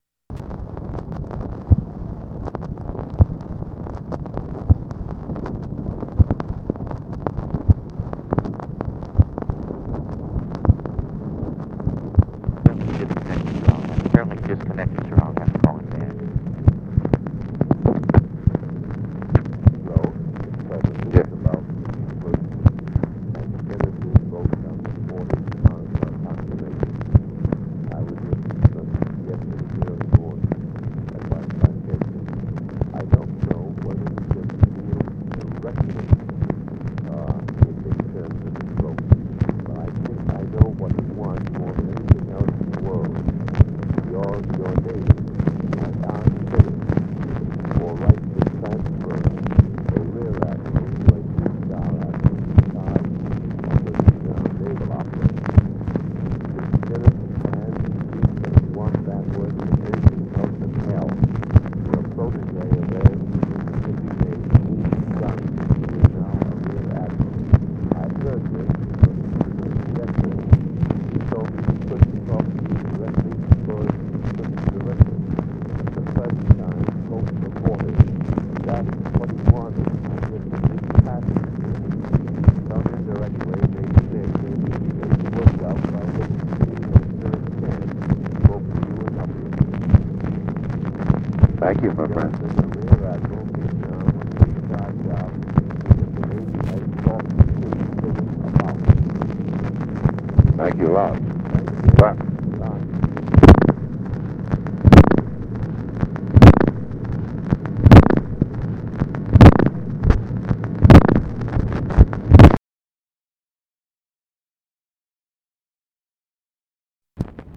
Conversation with TOMMY CORCORAN and TELEPHONE OPERATOR, July 17, 1968
Secret White House Tapes | Lyndon B. Johnson Presidency